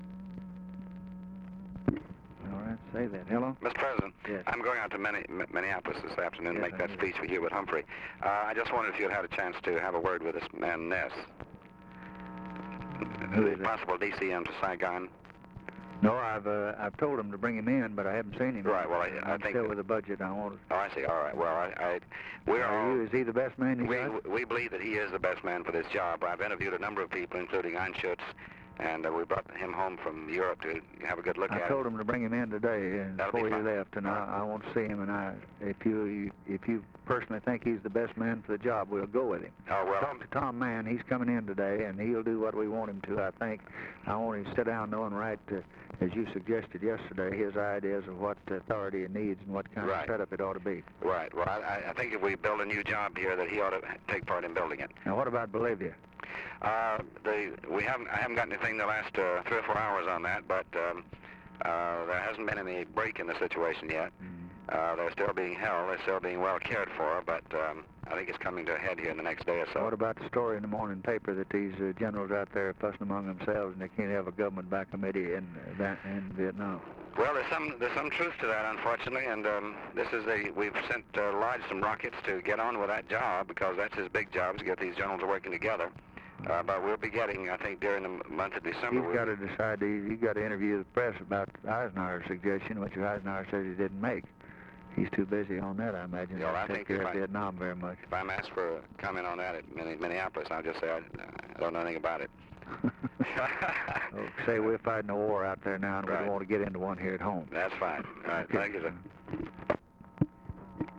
Conversation with DEAN RUSK, December 10, 1963
Secret White House Tapes